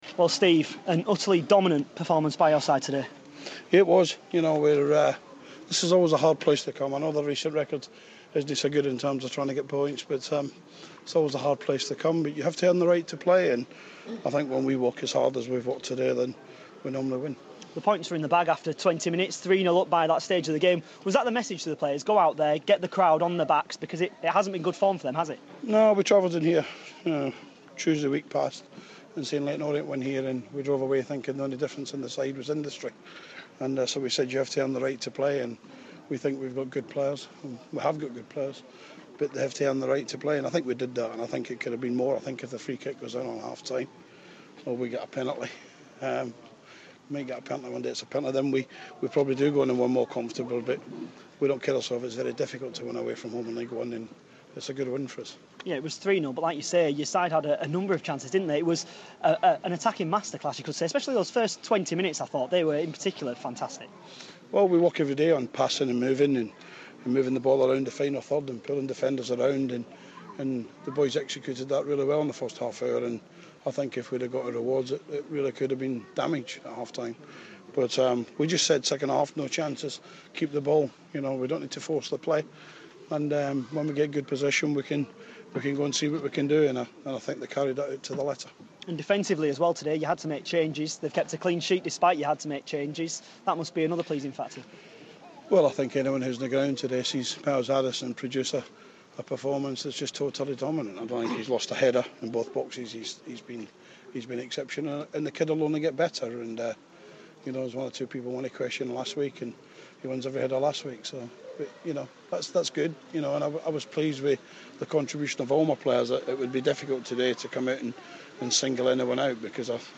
The Millers boss in conversation